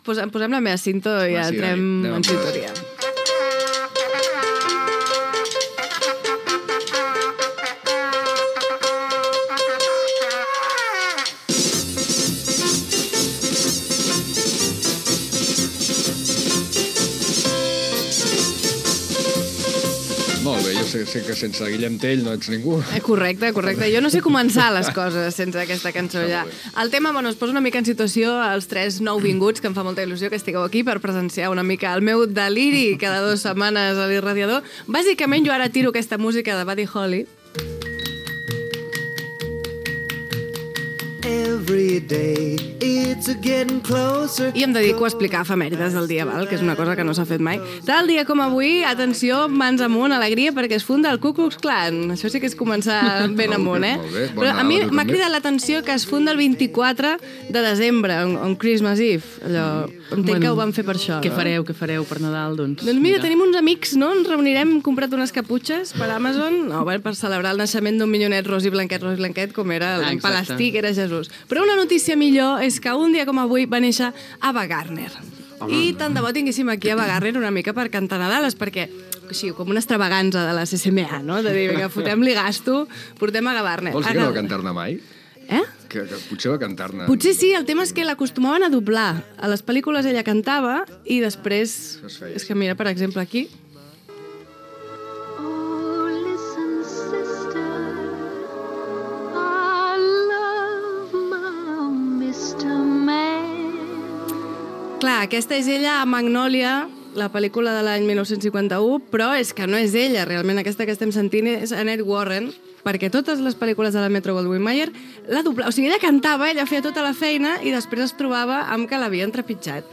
Cultura
FM